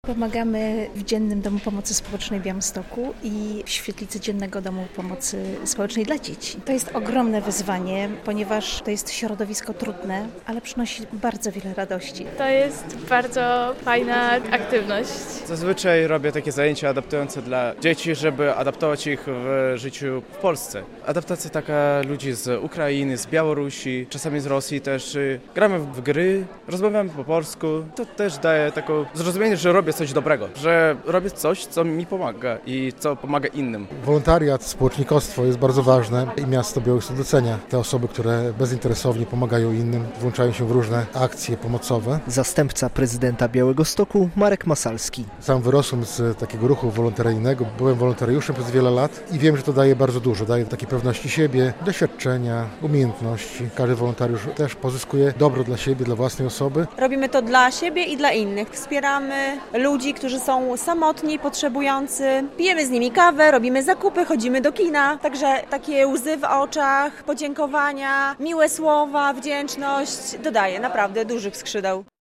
Medale "Za pracę na rzecz społeczności" podczas Gali Wolontariatu w Białymstoku otrzymali najaktywniejsi miejscy wolontariusze.
relacja